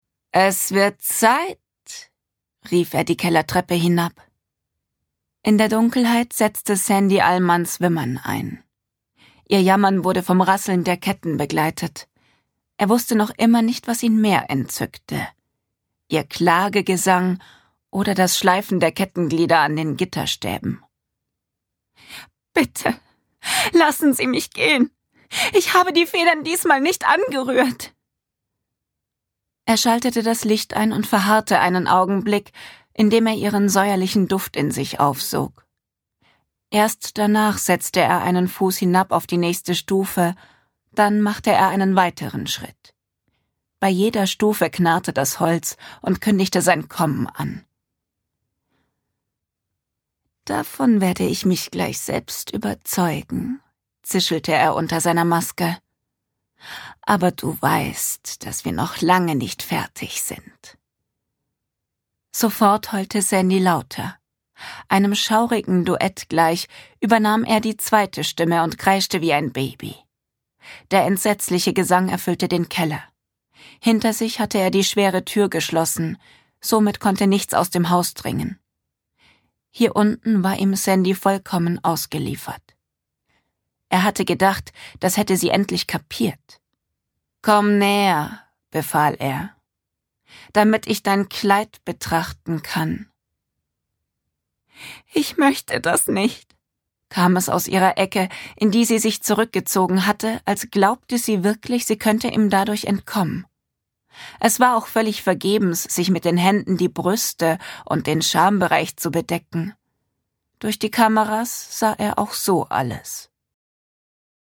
Hörporbe